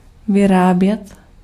Ääntäminen
Synonyymit yield engender Ääntäminen US : IPA : [pɹə.ˈdjus] US : IPA : /ˈpɹoʊ.dus/ Tuntematon aksentti: IPA : /pɹə.ˈdjuːs/ IPA : /pɹəˈd͡ʒuːs/ IPA : /pɹə.ˈdus/ IPA : /ˈpɹɒd.juːs/ IPA : /ˈpɹɒd͡ʒuːs/ US : IPA : /ˈpɹɑ.dus/